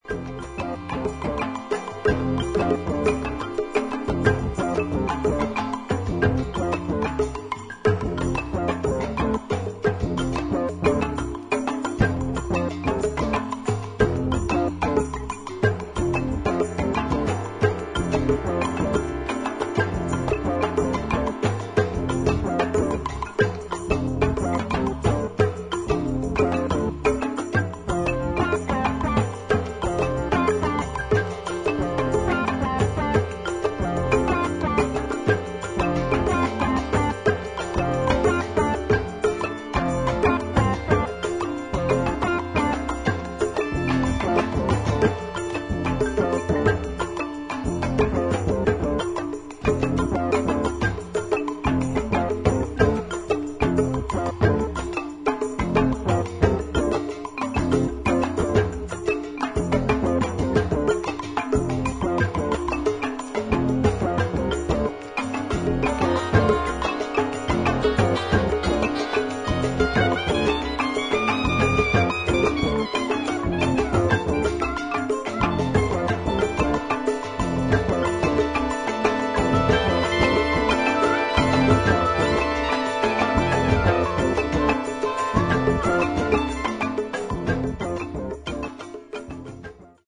土着的なアフリカものからニューウェーブ、ダブ、ロック、現代音楽までジャンルをクロスオーバーした良作が多数収録